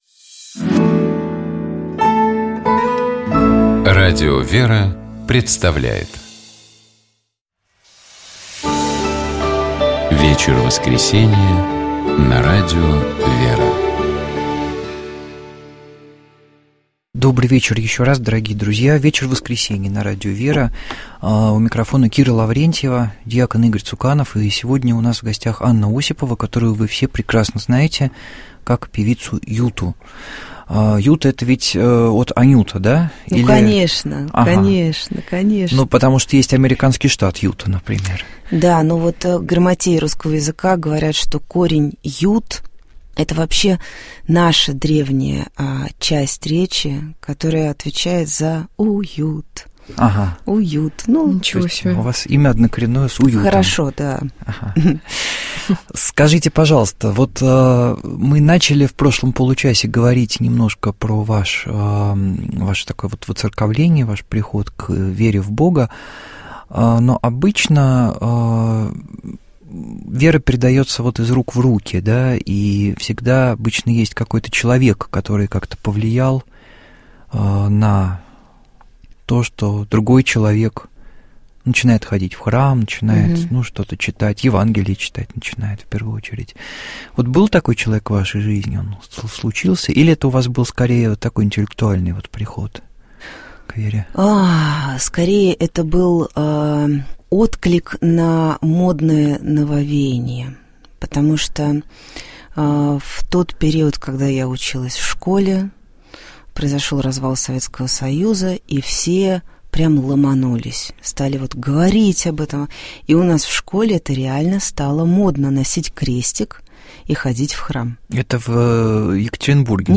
У нас в гостях была певица Юта.